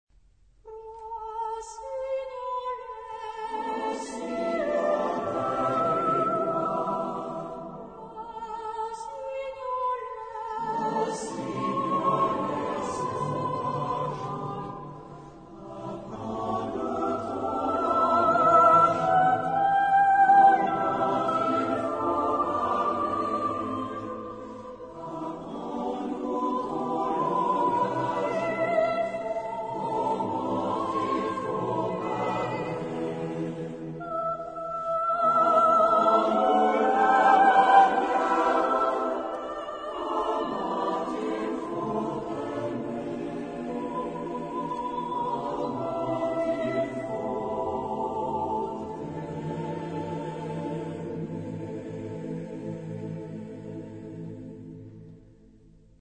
Genre-Style-Form: Secular ; Popular
Mood of the piece: cantabile ; moderate
Type of Choir: SATBarB  (5 mixed voices )
Soloist(s): Soprano (1)  (1 soloist(s))
Tonality: A aeolian